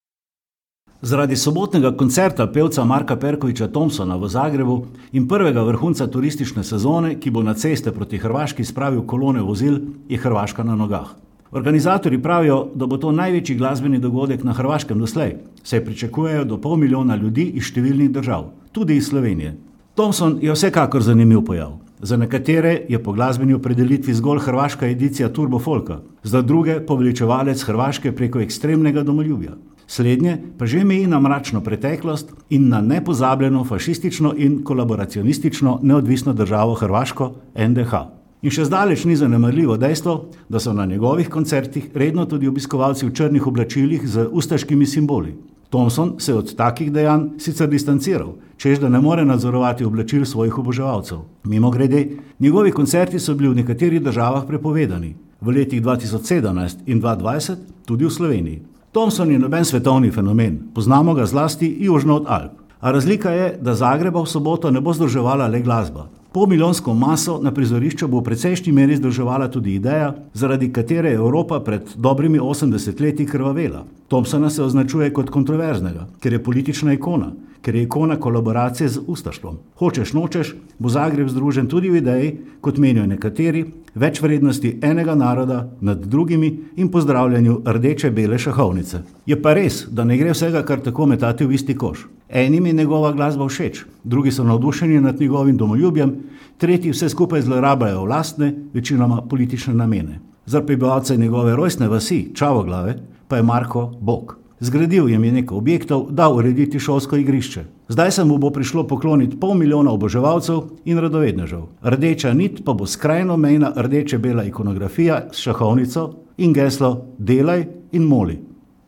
Komentar je stališče avtorja in ne nujno uredništva